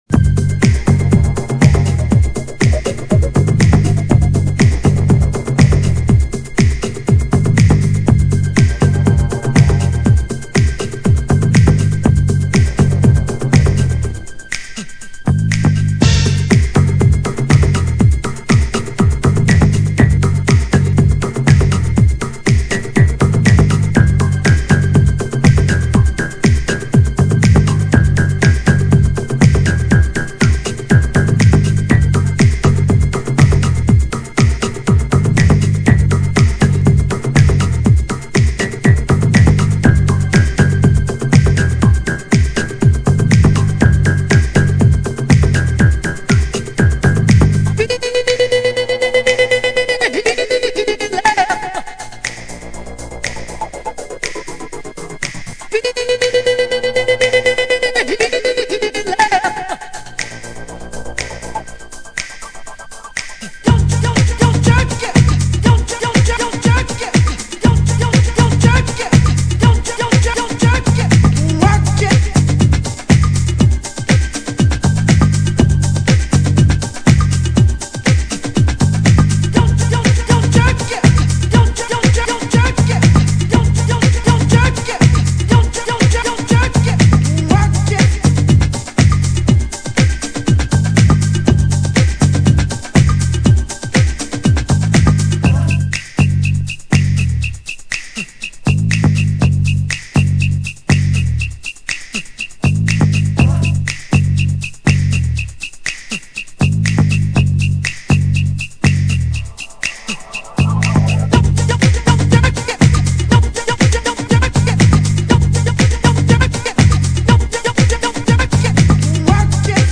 グレイテストUKハウス・クラシック！！